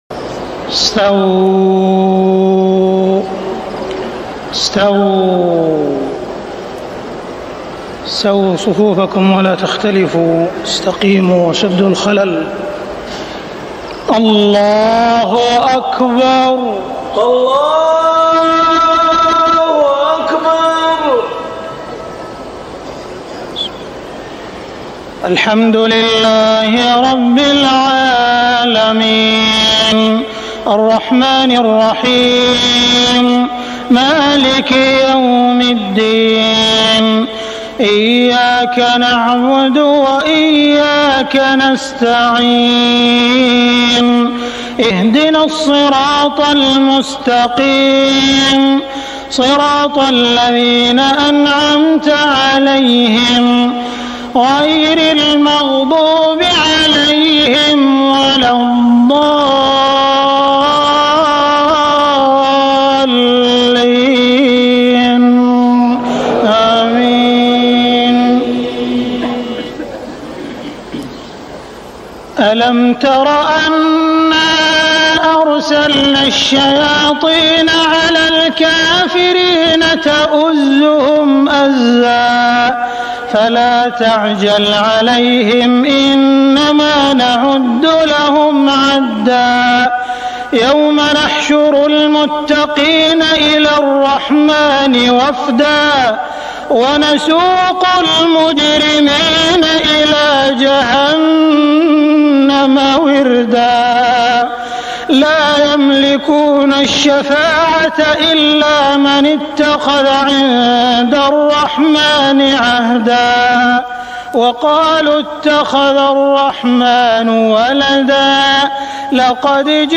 صلاة العشاء 1421هـ خواتيم سورة مريم 83-98 > 1421 🕋 > الفروض - تلاوات الحرمين